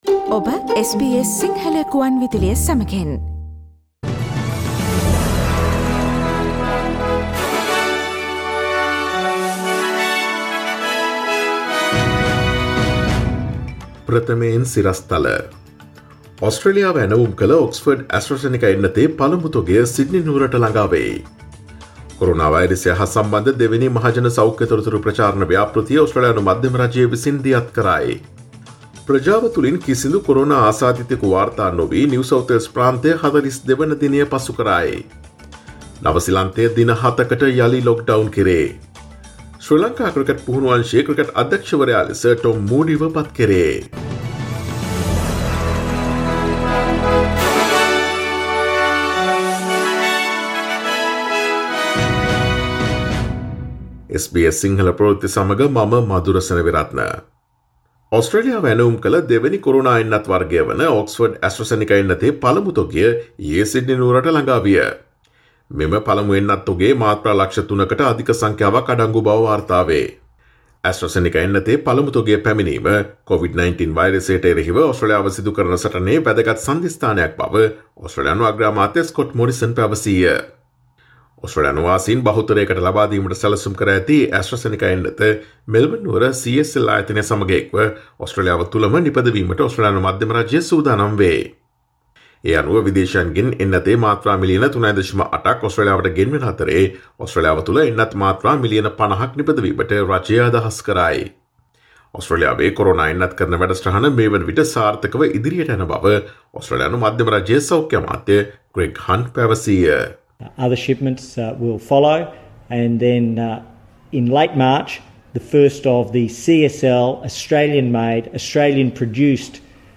SBS සිංහල සේවයේ 2021 මාර්තු 01 වන දා සඳුදා වැඩසටහනේ ප්‍රවෘත්ති ප්‍රකාශය.